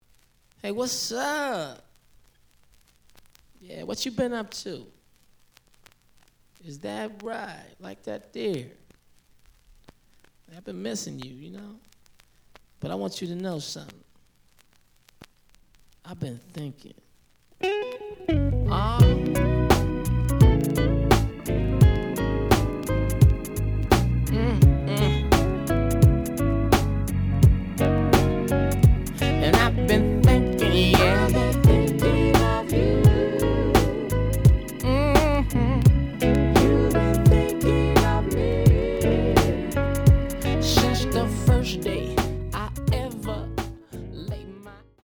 The audio sample is recorded from the actual item.
●Genre: Hip Hop / R&B
Slight click noise on beginning of B side, but almost good.)